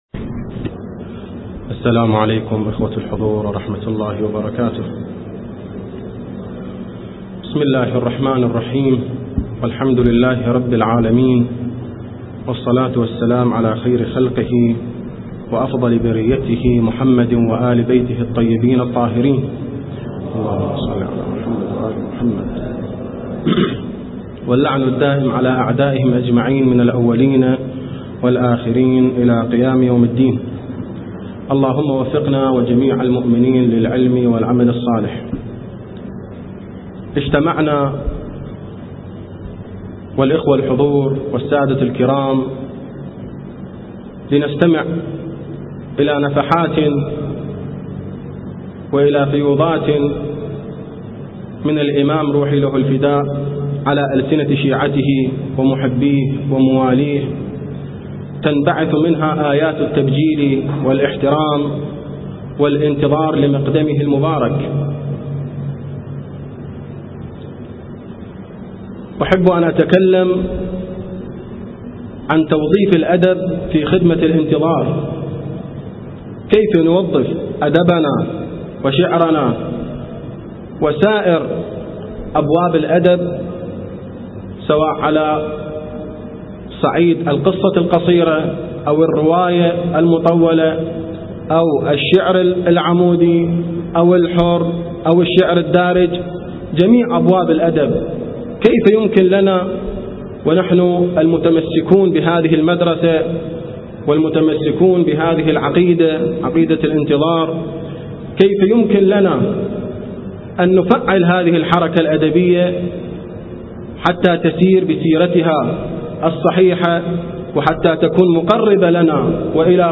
كلمة
في مهرجان الشعر الاول لشعراء العراق